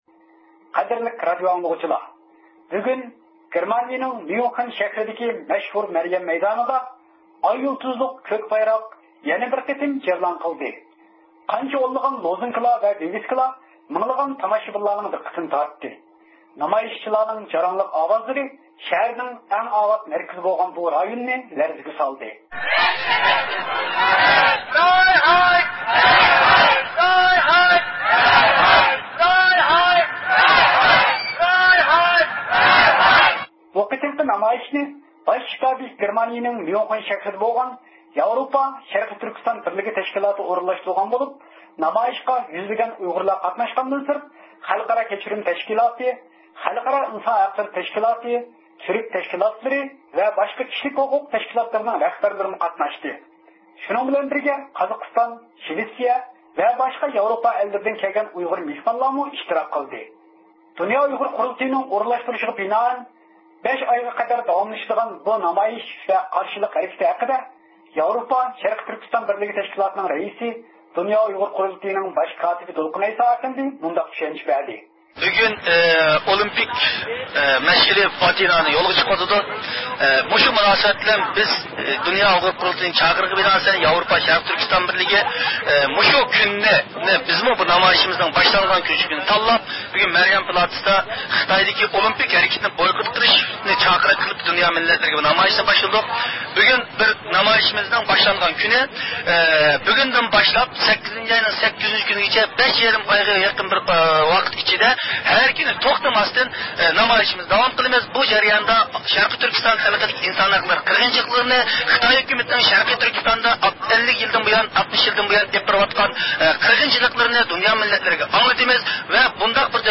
نامايىشچىلارنىڭ جاراڭلىق ئاۋازلىرى شەھەرنىڭ ئەڭ ئاۋات مەركىزى بولغان بۇ رايوننى لەرزىگە سالدى.
كەڭ كۆلەملىك نامايىش
نامايىش جەريانىدا „خىتايلار ۋەتىنىمىزدىن چىقىپ كەتسۇن، ئۇيغۇرلارغا ئەركىنلىك، ئۇيغۇرلارغا دېموكراتىيە، ئىنسان ھەقلىرى بولمىغان دۆلەتتە ئولىمپىك ئۆتكۈزۈشكە بولمايدۇ، خىتايدىكى ئۆلۈم جازاسى ئەمەلدىن قالدۇرۇلسۇن، سىياسىي مەھبۇسلار قويۇپ بېرىلسۇن، دېگەندەك شوئارلار ئۈزمەي توۋلىنىپ تۇردى.